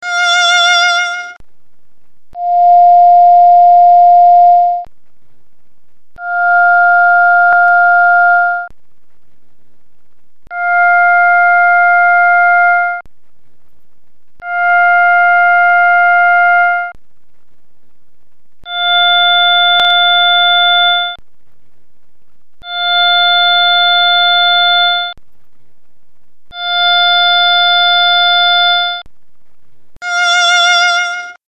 Praktisk analyse af en violintone
Lyt til en violintone og hvordan man kan høre
grundsvingning og ovettoner lagt på lag på lag til tidspukt 0.44
violin044.mp3